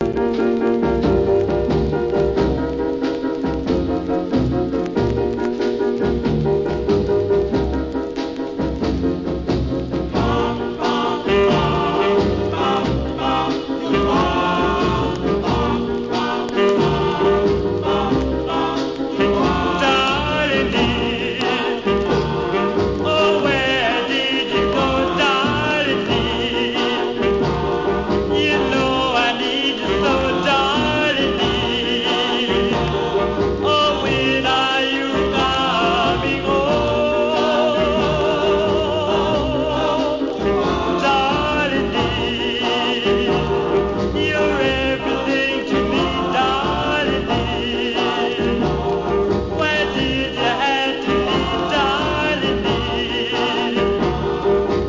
1. SOUL/FUNK/etc...
1954年のKILLER DOO-WOPバラード!!